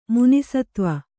♪ muni satva